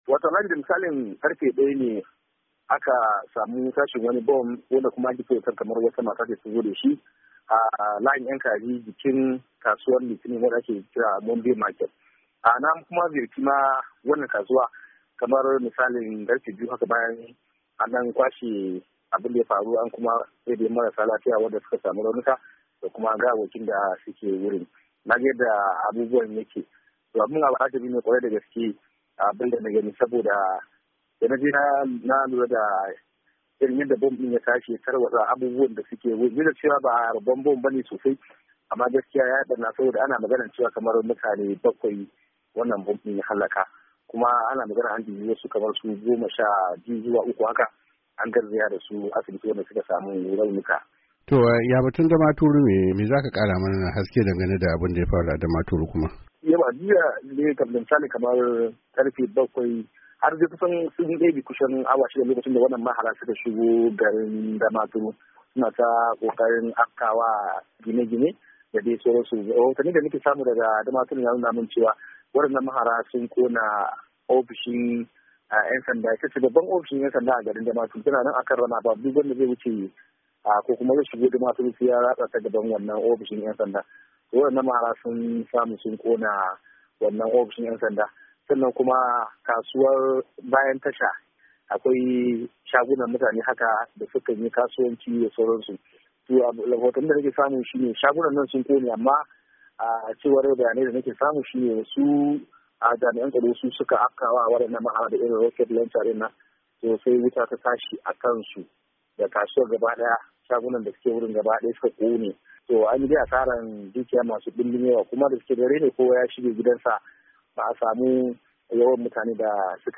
Rahoton harin Maiduguri da Potiskum